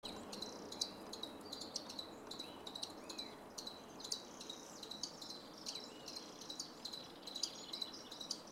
Picolezna Patagónico (Pygarrhichas albogularis)
Nombre en inglés: White-throated Treerunner
Fase de la vida: Adulto
Localidad o área protegida: Ruta de los 7 Lagos
Condición: Silvestre
Certeza: Fotografiada, Vocalización Grabada